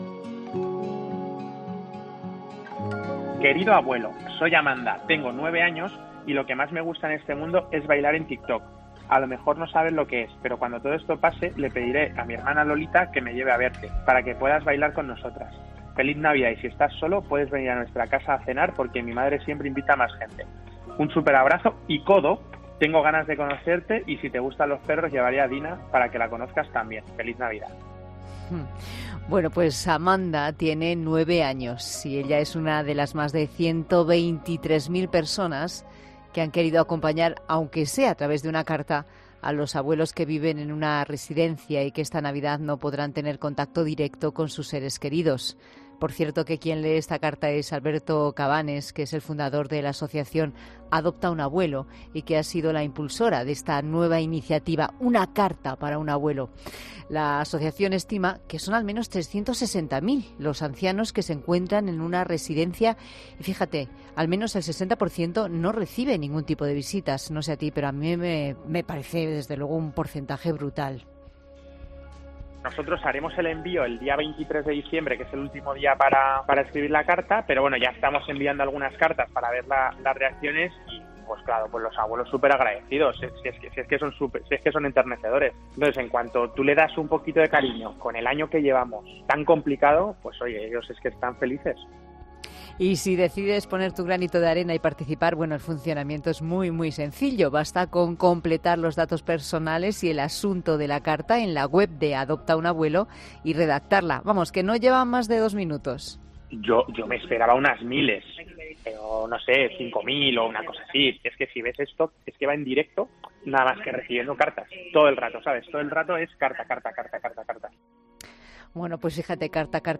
Idoia Villanueva es eurodiputada y en 'La Tarde' ha indicado que la pandemia "ha puesto de manifiesto los fallos que estaban ahí".